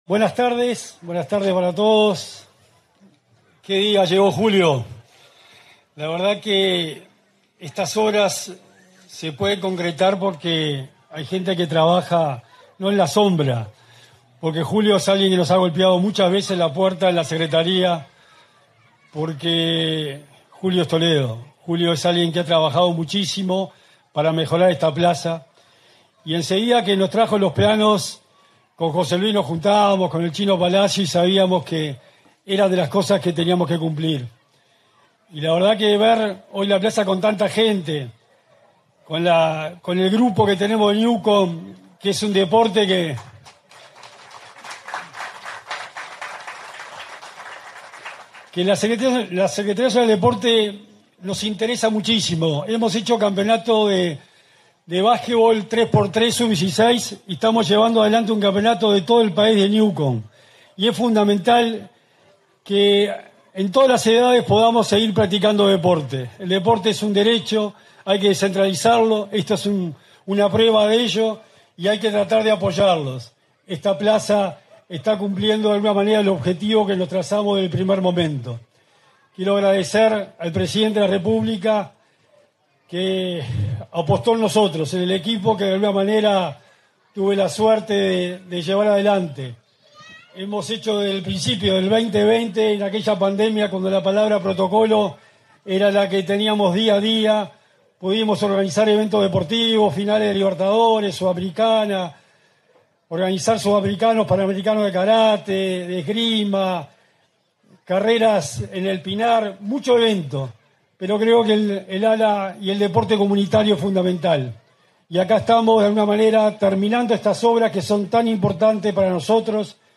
Palabras del secretario nacional del Deporte, Sebastián Bauzá
Palabras del secretario nacional del Deporte, Sebastián Bauzá 14/10/2024 Compartir Facebook X Copiar enlace WhatsApp LinkedIn En el marco de la ceremonia de inauguración de obras en la plaza de deportes de Toledo, este 14 de octubre, se expresó el secretario nacional del Deporte, Sebastián Bauzá.